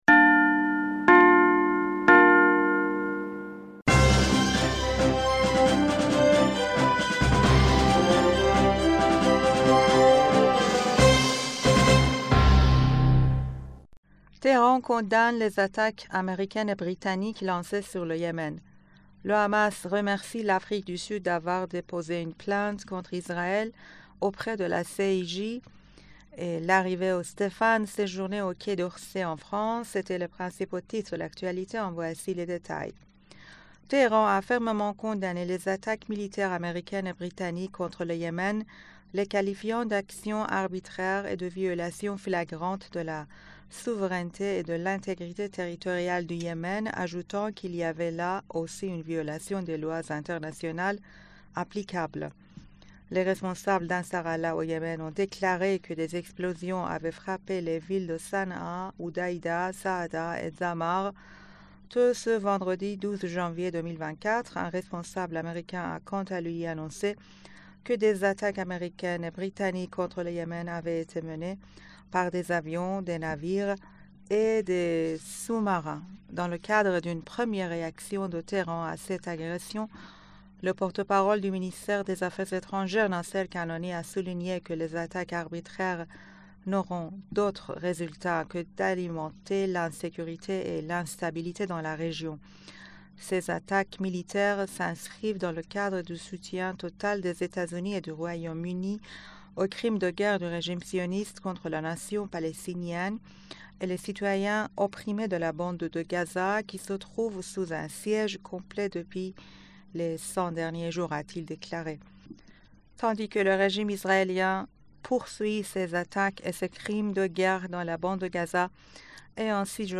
Bulletin d'information du 12 Janvier 2024